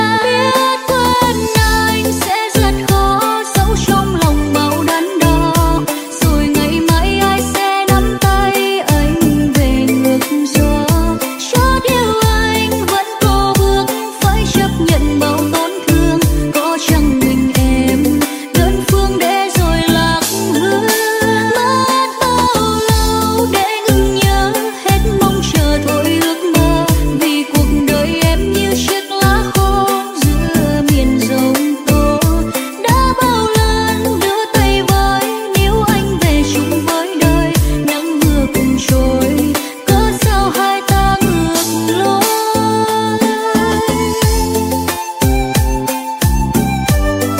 Nhạc Rumba.